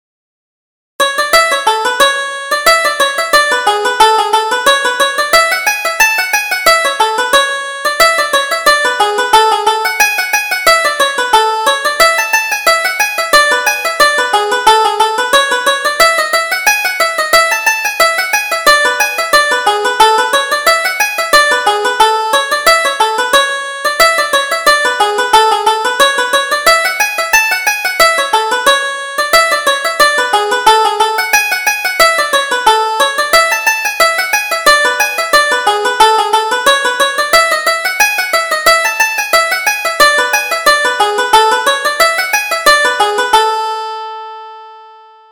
Reel: Robin Redbreast